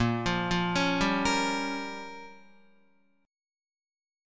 A#m6 Chord